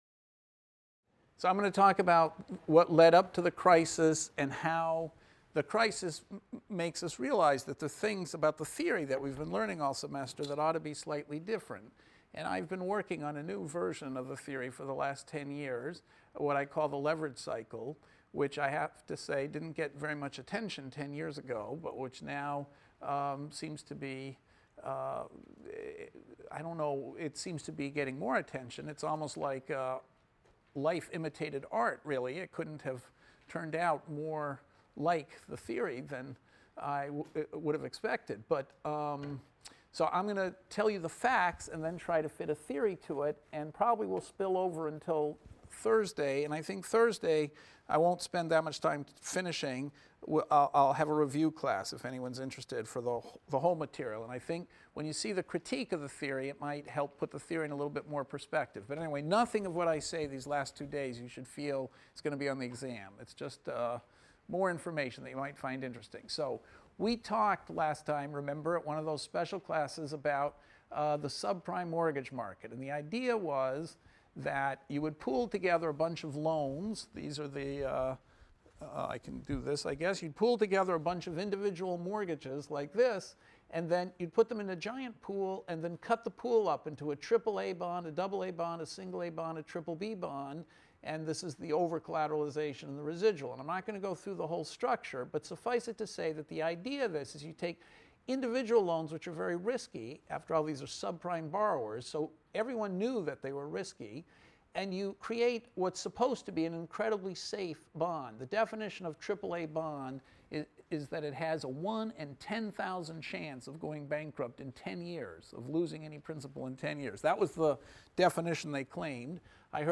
ECON 251 - Lecture 25 - The Leverage Cycle and the Subprime Mortgage Crisis | Open Yale Courses